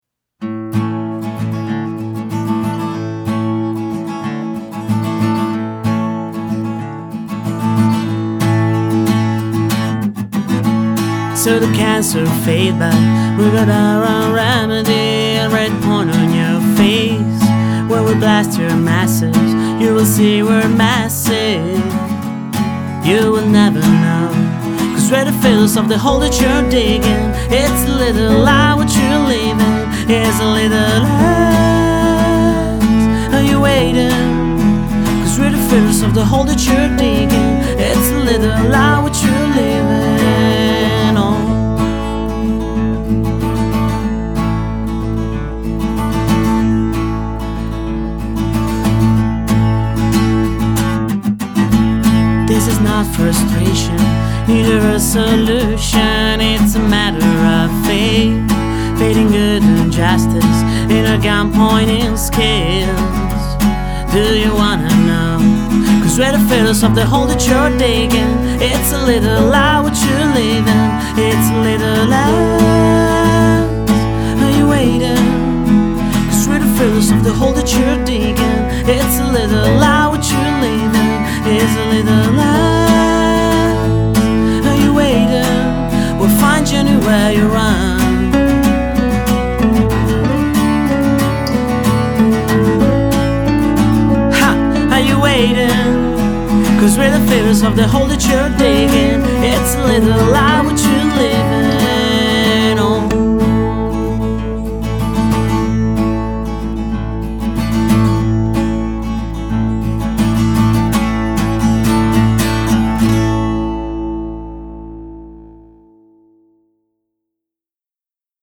GenereWorld Music / Folk